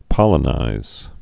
(pŏlə-nīz)